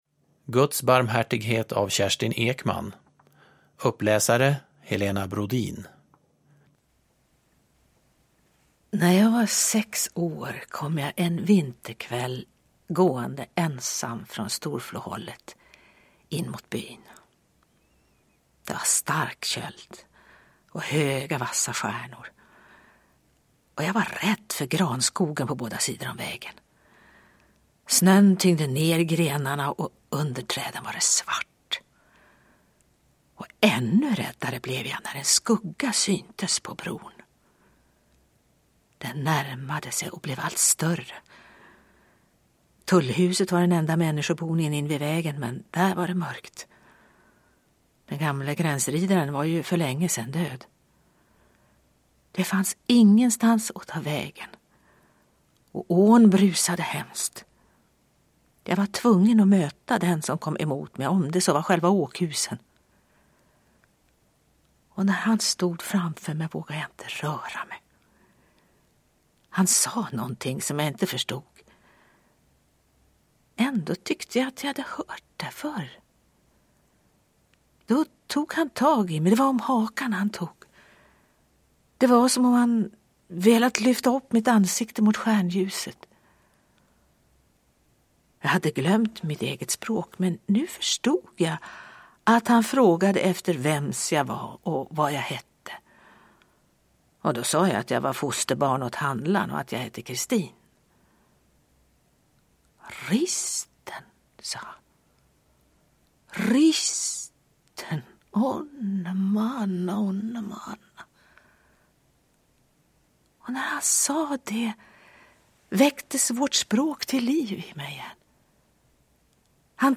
Uppläsare: Helena Brodin
Ljudbok